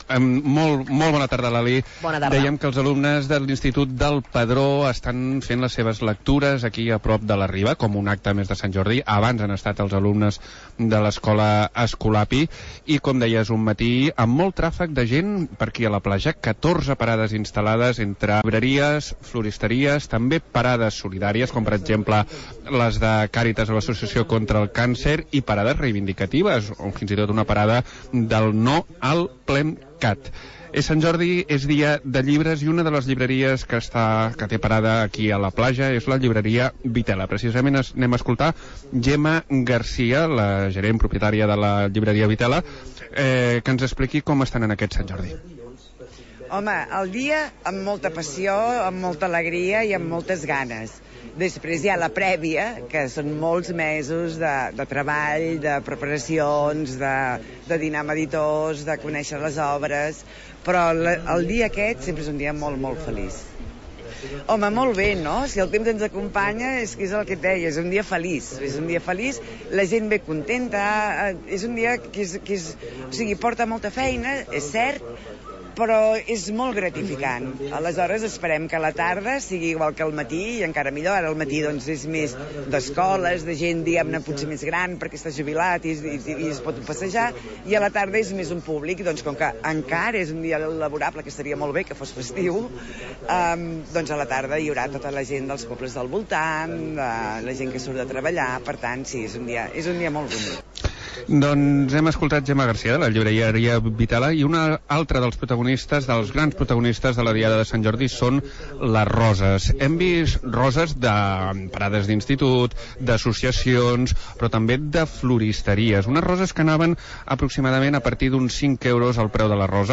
Resum del programa especial de Sant Jordi de Ràdio l'Escala, fet des la Platja de les Barques